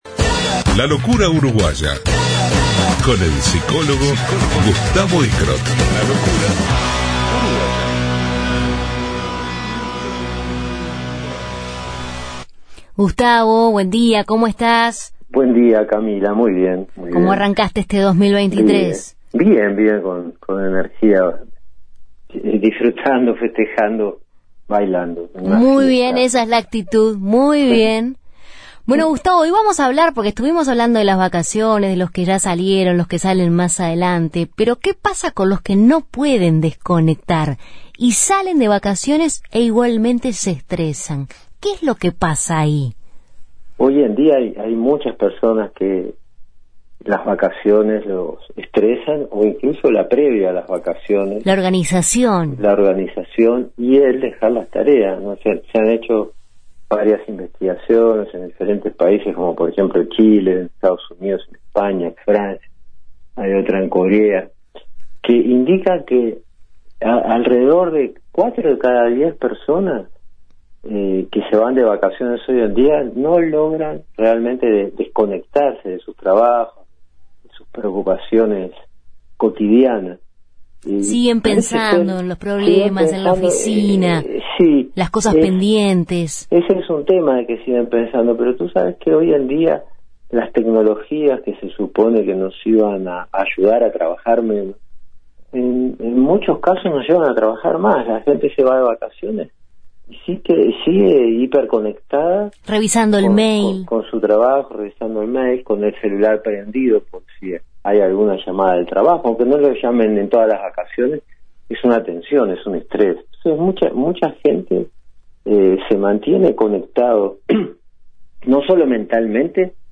Columna